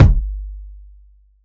• Fat Kick Sound G Key 541.wav
Royality free kick drum single hit tuned to the G note. Loudest frequency: 217Hz
fat-kick-sound-g-key-541-KLi.wav